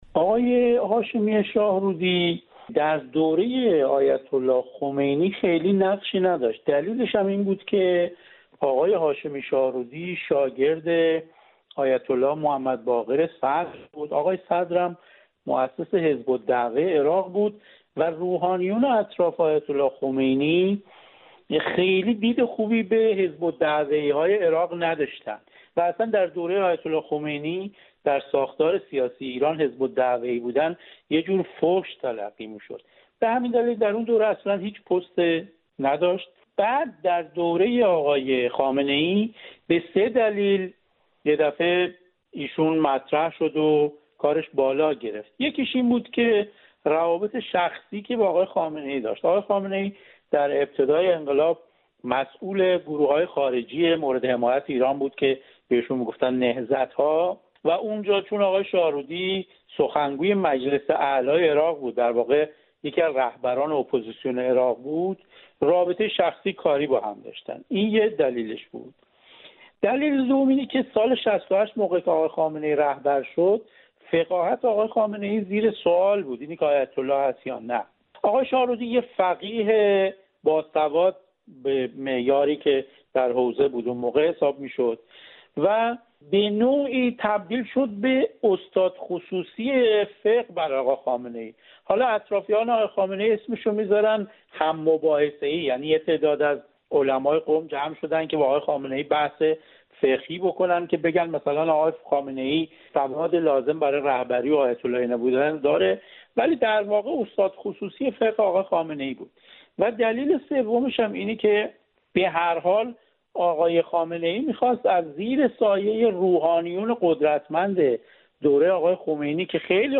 ارزیابی دو تحلیلگر سیاسی از سابقه و جایگاه محمود هاشمی شاهرودی در قدرت